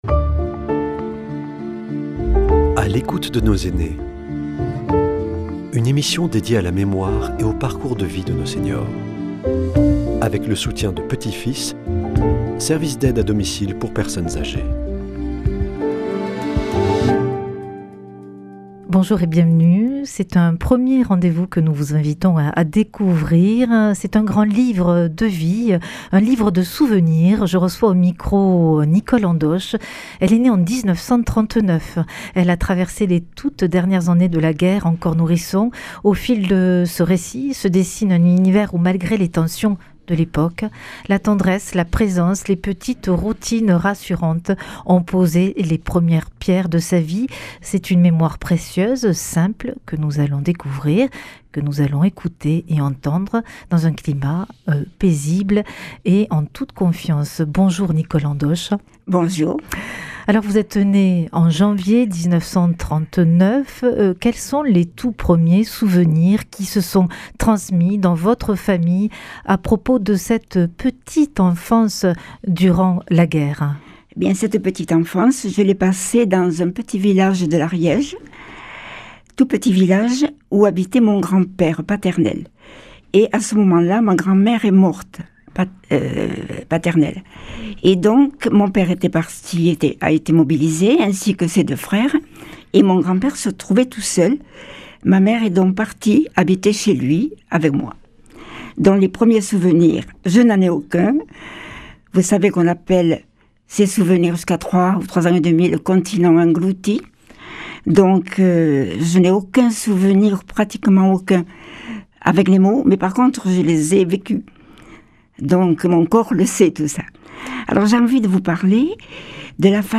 Ce premier rendez-vous de À l’écoute de nos aînés nous invite à découvrir une mémoire précieuse, un témoignage intime, raconté dans un climat paisible et en confiance, pour entrer en douceur dans une vie marquée par l’Histoire et les liens humains.